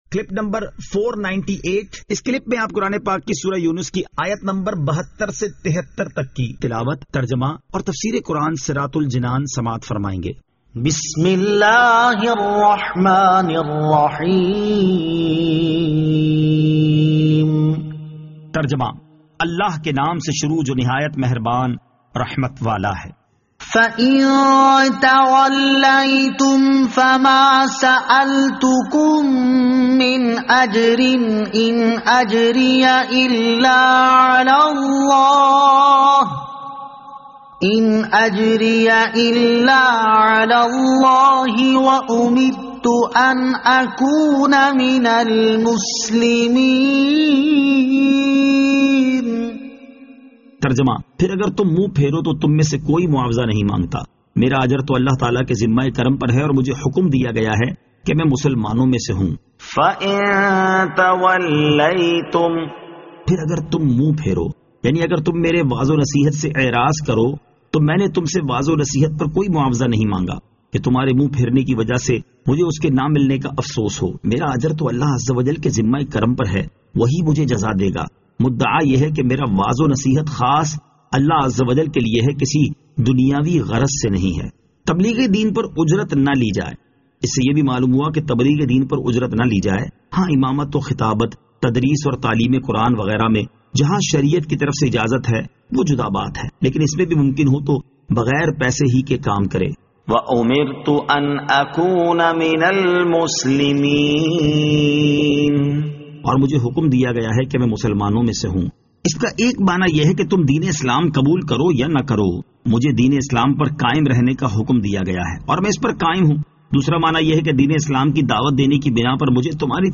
Surah Yunus Ayat 72 To 73 Tilawat , Tarjama , Tafseer